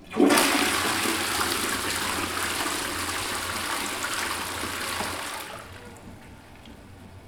• water flush water closet.wav
Recorded with Tascam DR 40
water_flush_water_closet_x0Q.wav